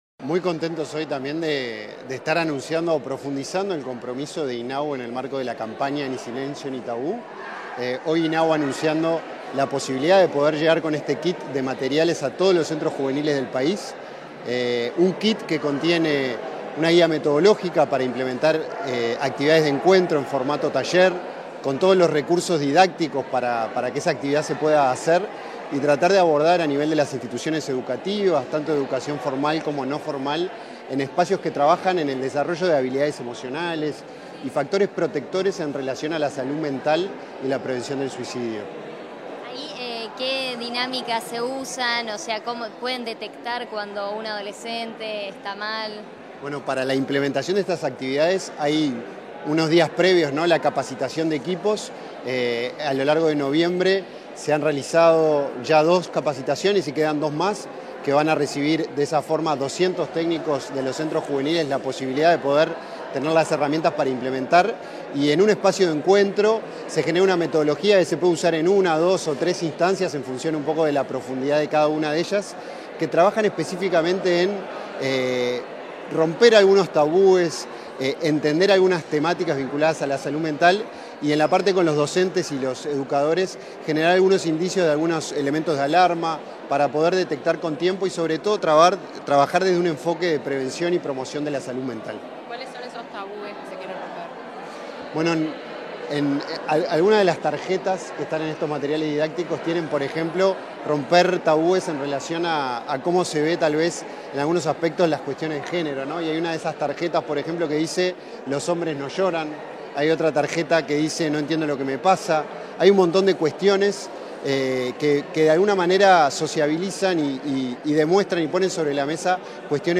Declaraciones a la prensa del presidente del INJU, Felipe Paullier
Tras participar en la entrega de los kits informativos en el marco de la campaña sobre salud mental y prevención del suicidio impulsada por el Ministerio de Desarrollo Social, llamada Ni Silencio Ni Tabú, este 15 de noviembre, el presidente del Instituto Nacional de la Juventud (INJU), Felipe Paullier, realizó declaraciones a la prensa.